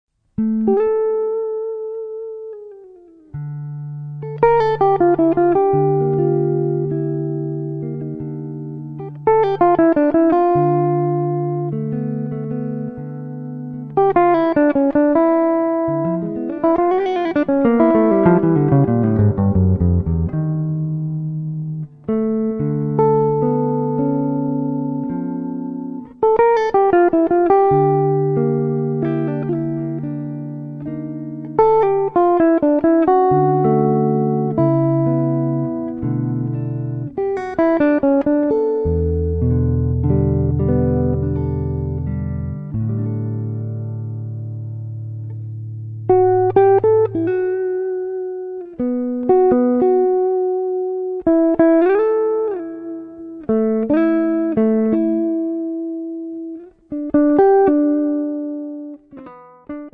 chitarre